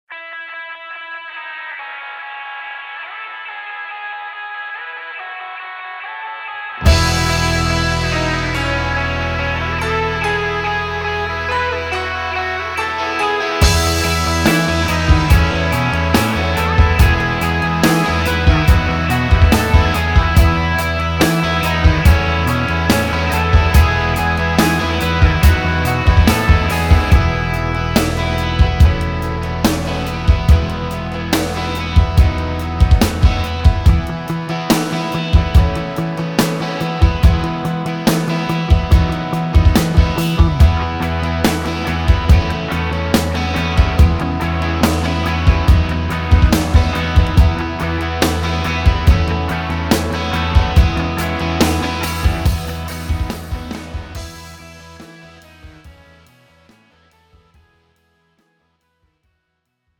팝송